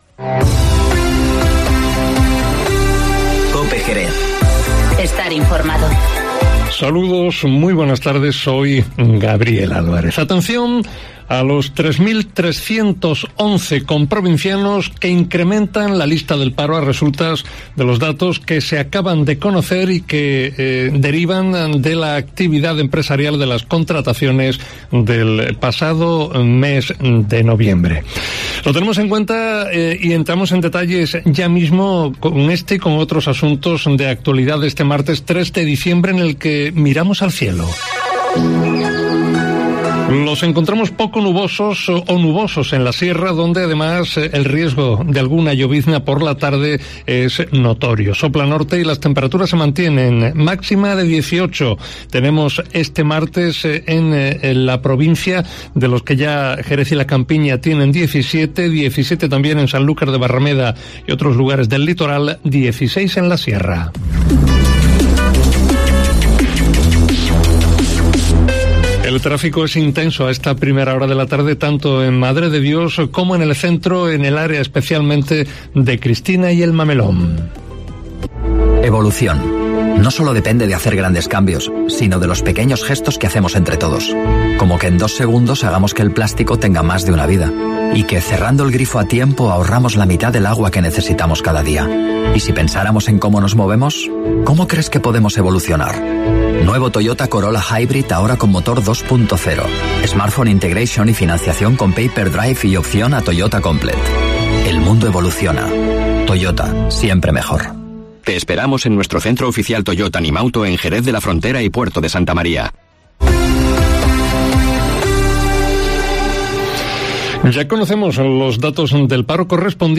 Informativo Mediodía COPE en Jerez 03-12-19